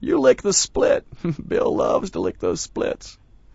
gutterball-3/Gutterball 3/Commentators/Bill/b_youlickedthesplits.wav at 893fa999aa1c669c5225bd02df370bcdee4d93ae